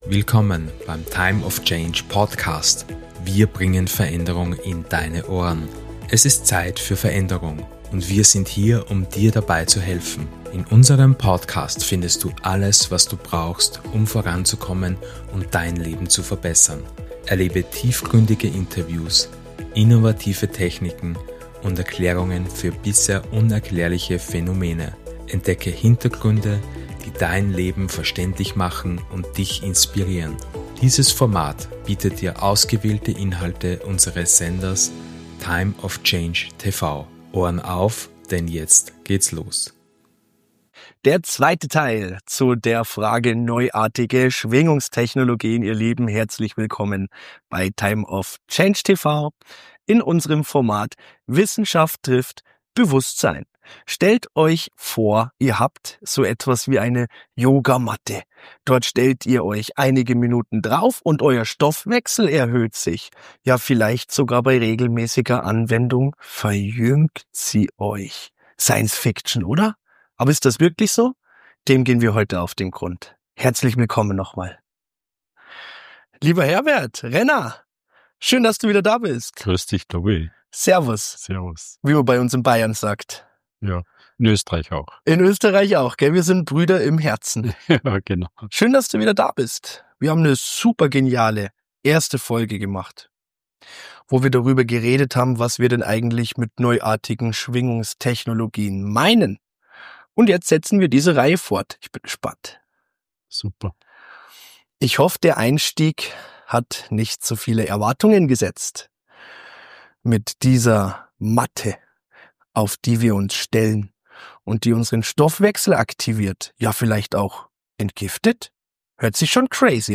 Sollte Dir dieses Interview gefallen haben, freuen wir uns über Dein Like und wenn Du diesen Podcast mit Deinen Mitmenschen teilst!